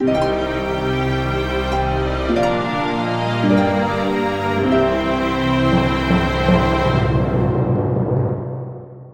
描述：管弦乐循环（弦乐、竖琴和定音鼓）
Tag: 105 bpm Orchestral Loops Strings Loops 1.54 MB wav Key : Unknown